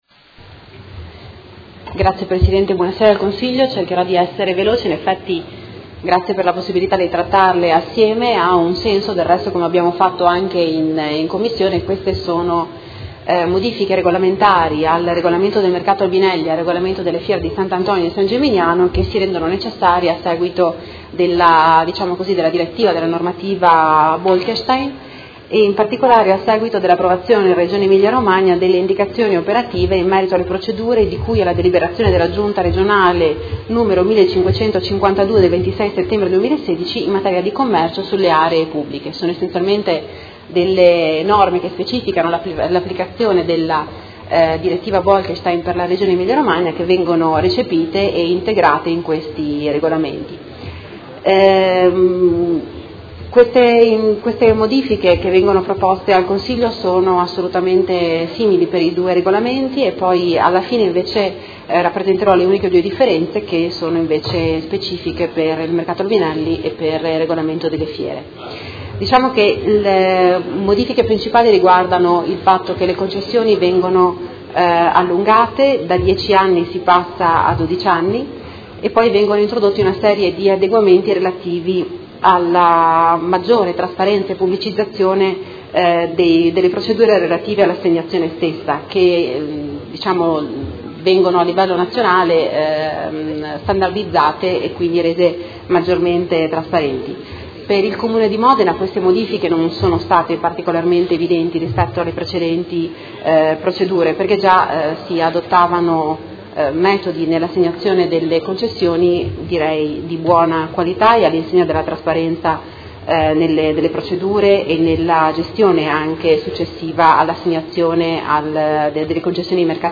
Seduta del 20/07/2017 Presenta Delibera: Regolamento Mercato Coperto Albinelli – Approvazione modifiche e Delibera: Regolamento della Fiera di S. Antonio e San Geminiano – Approvazione modifiche